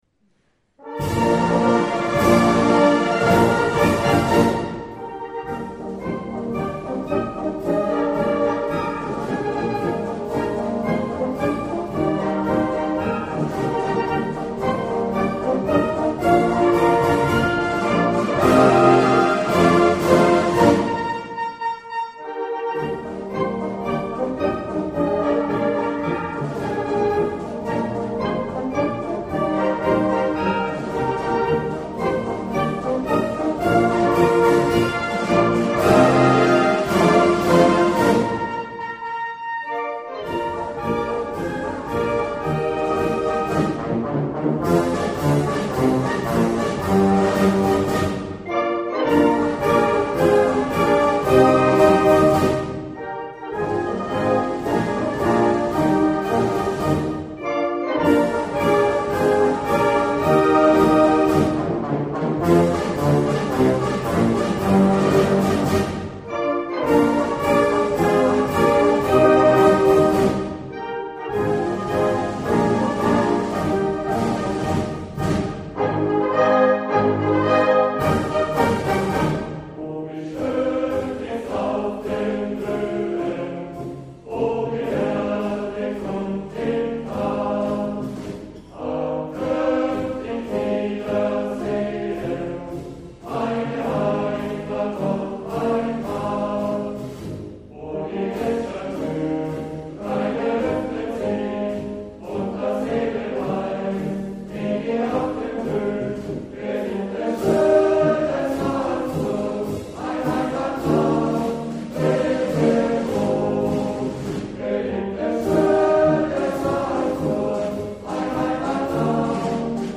Blasmusik & Orchester